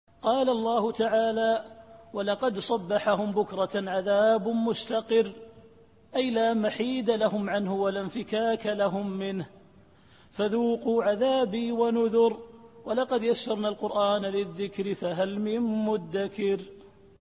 التفسير الصوتي [القمر / 38]